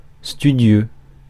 Ääntäminen
Synonyymit appliqué Ääntäminen France: IPA: [sty.djø] Haettu sana löytyi näillä lähdekielillä: ranska Käännös Adjektiivit 1. lernema Suku: m .